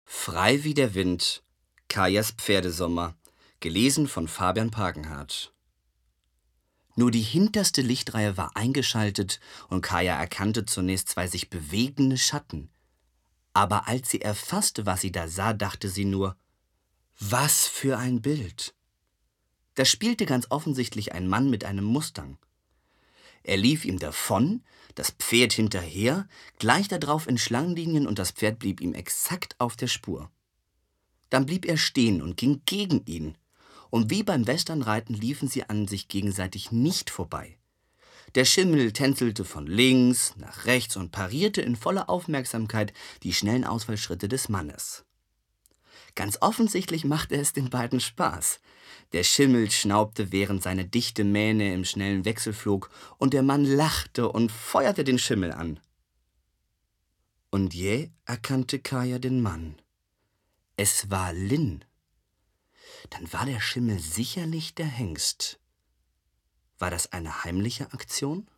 Hoerbuch.mp3